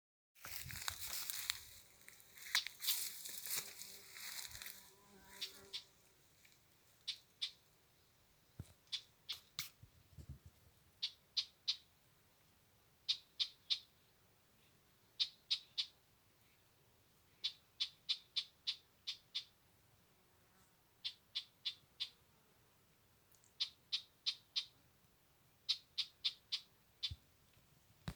Red-backed Shrike, Lanius collurio
StatusPair observed in suitable nesting habitat in breeding season